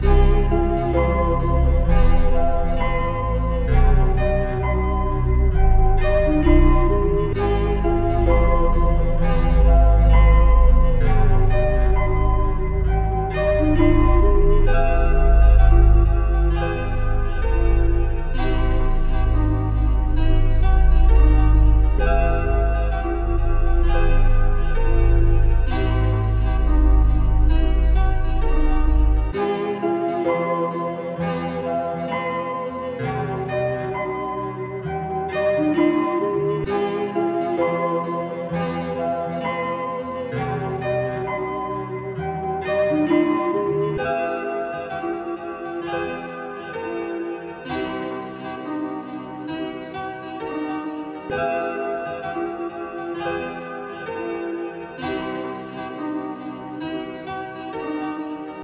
flame metro rodeo vibes 131bpm.wav